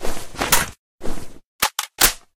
ru556_reload_part.ogg